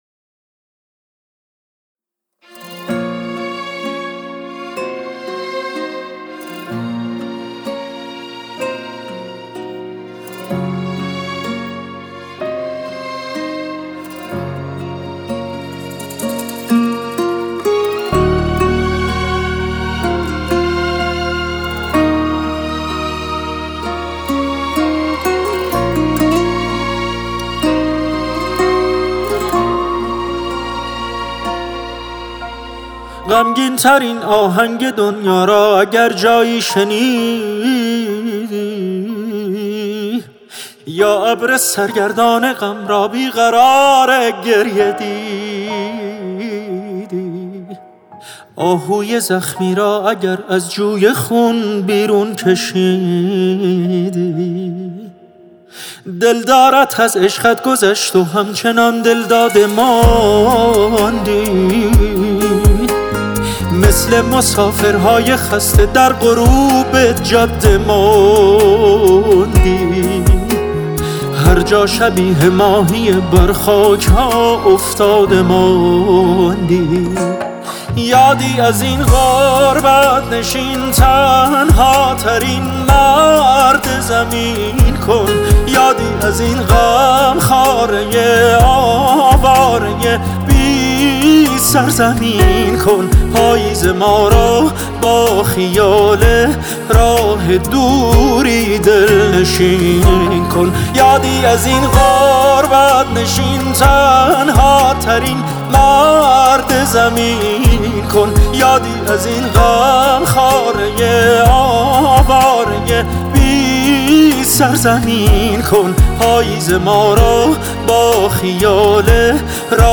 نوازنده گیتار
سازهای زهی